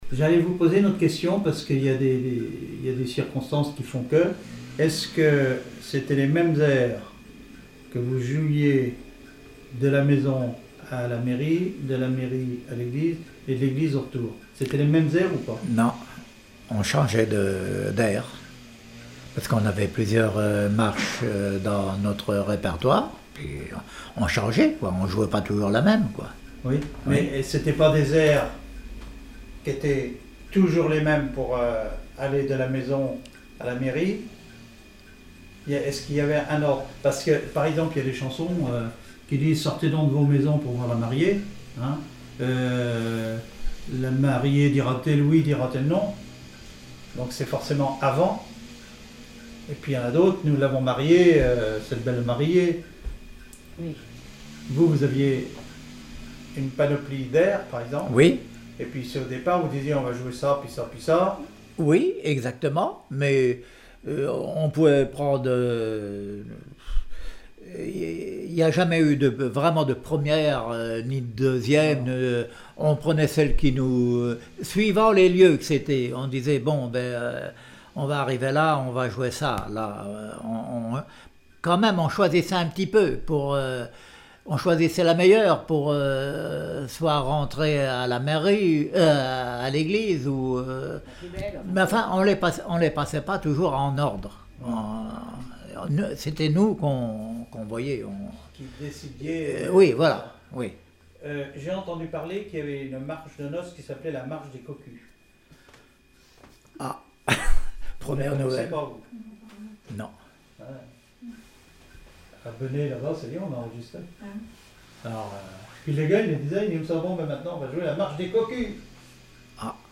clarinette, clarinettiste
Témoignage comme joueur de clarinette